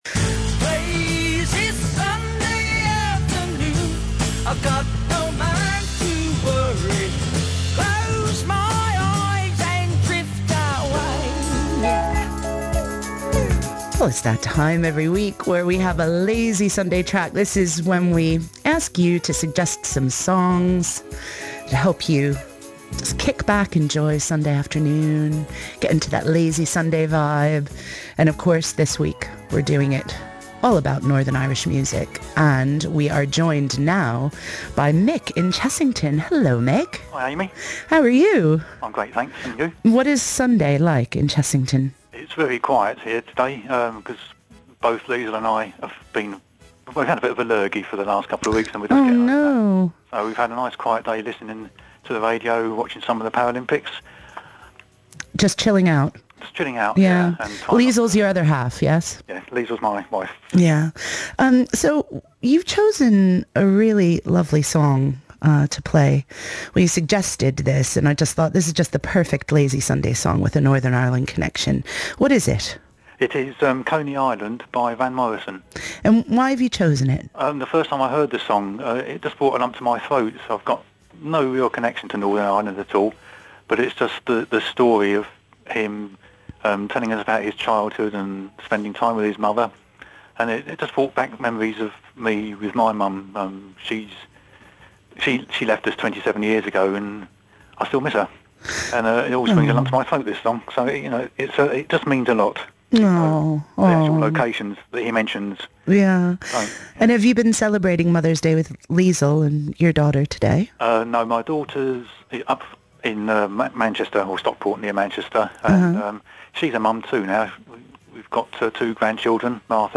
Well, I haven’t been on air for a long, long time.
The phone rang, I spoke to Amy
and she played my suggested song.